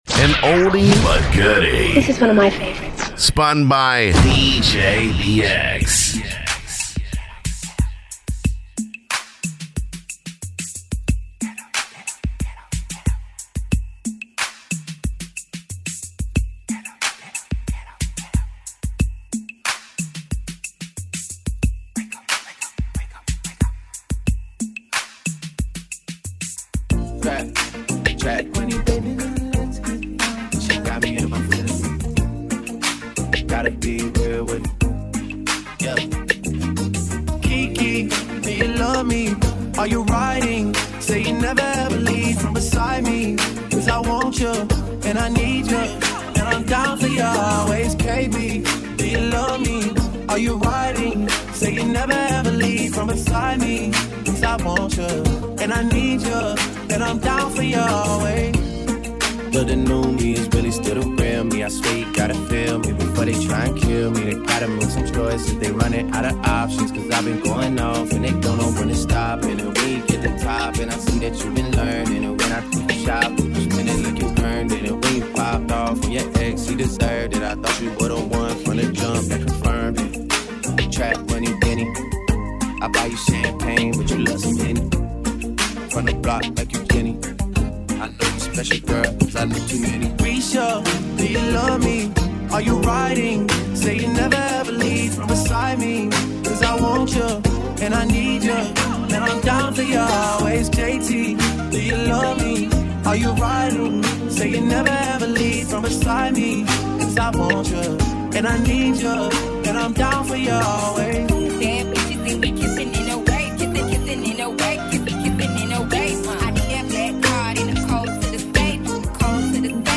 Acapellas
Mashup